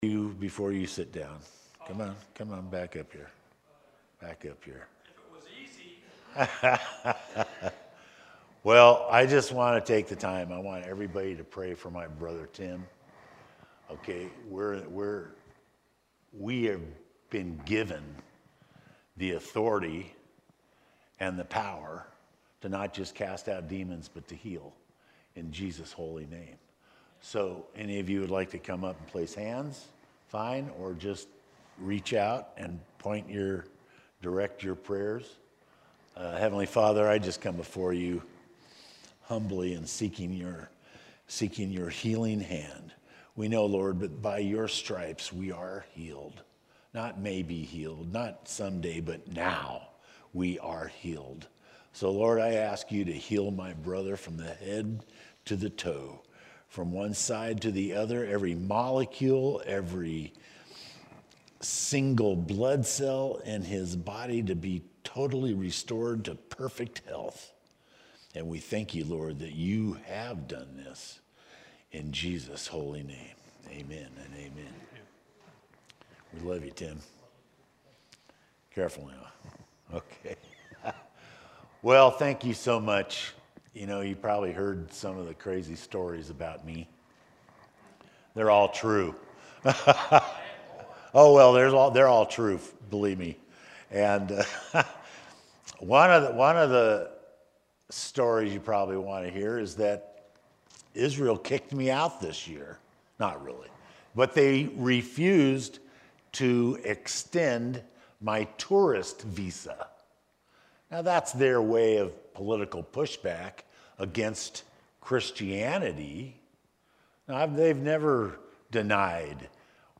Sermon-Jan-7-2024.mp3